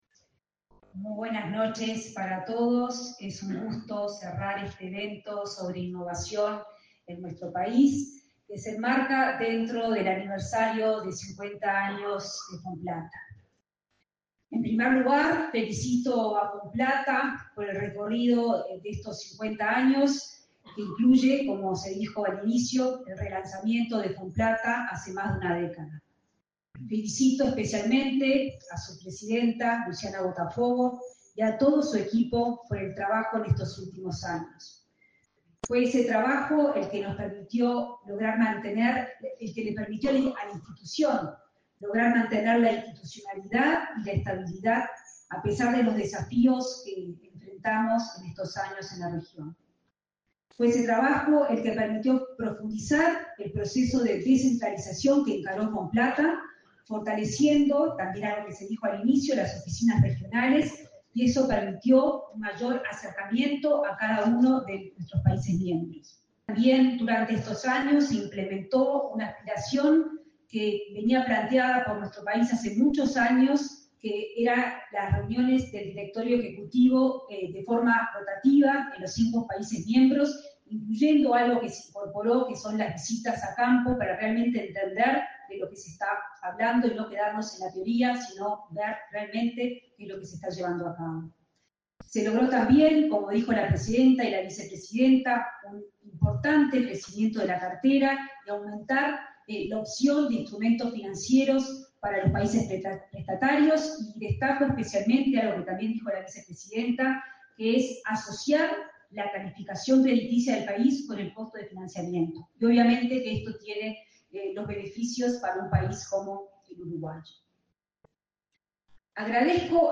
Palabras de la ministra de Economía y Finanzas, Azucena Arbeleche
La ministra de Economía y Finanzas, Azucena Arbeleche, participó, este 3 de diciembre, en el 50.° aniversario de Fonplata.